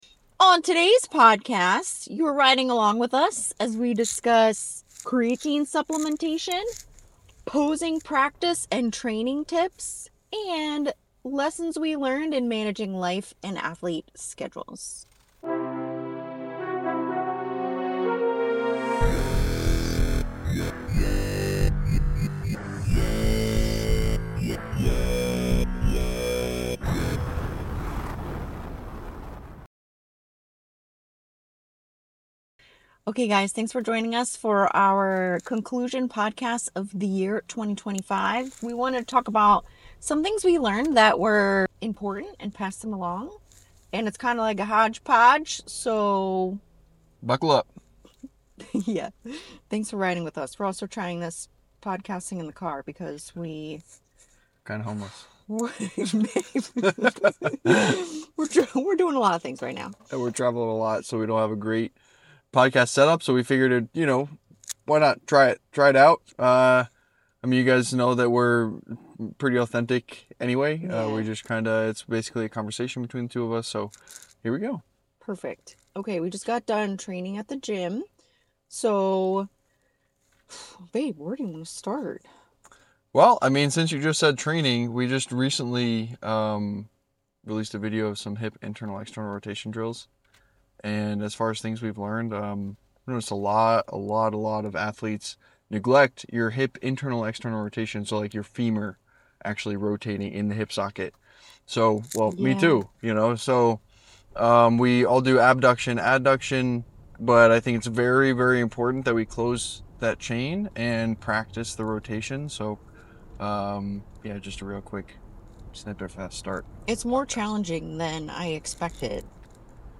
An Athlete Interview